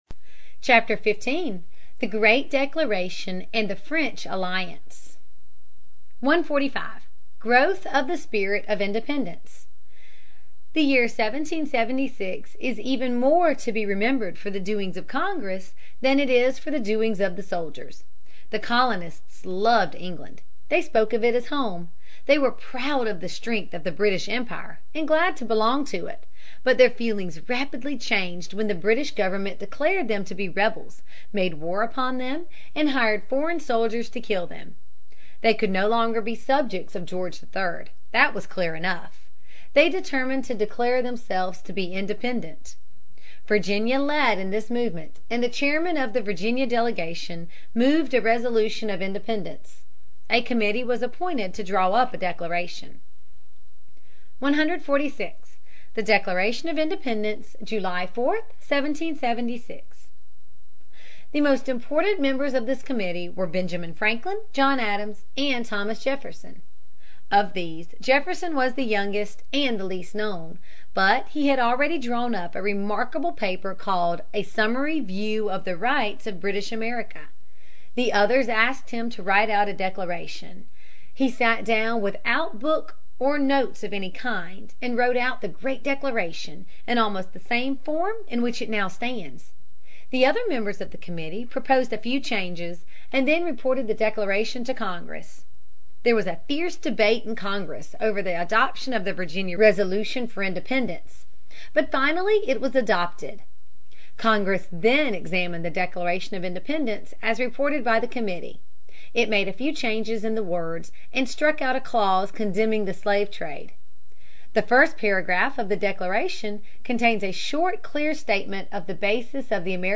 在线英语听力室美国学生历史 第48期:《独立宣言》和法国联盟(1)的听力文件下载,这套书是一本很好的英语读本，采用双语形式，配合英文朗读，对提升英语水平一定更有帮助。